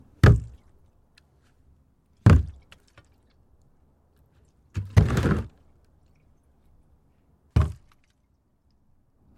随机的 "塑料煤气罐放下在人行道上满是沉重的砰砰声1
描述：塑料气体容器放在人行道全重thud1.wav
Tag: 扑扇 路面 向下 塑料 气体 容器 充分